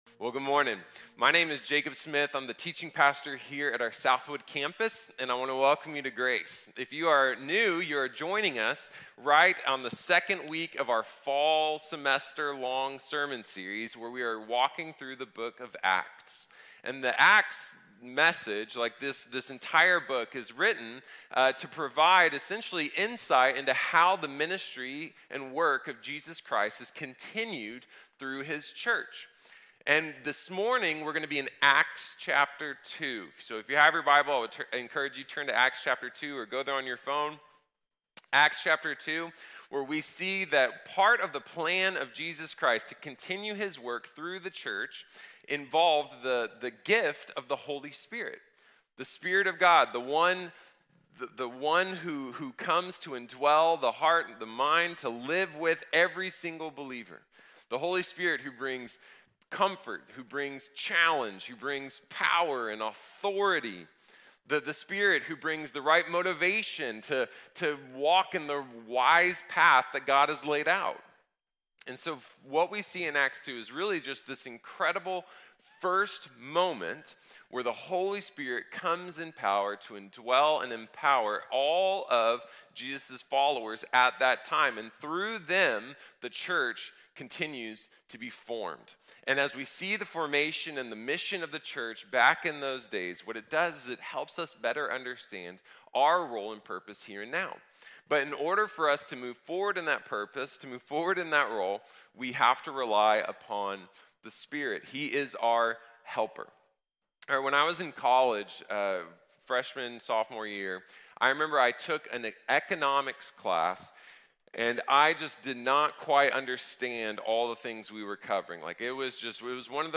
Pentecost | Sermon | Grace Bible Church